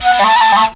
FLUTELIN04.wav